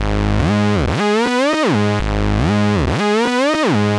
Wow _ Flutter Ab 120.wav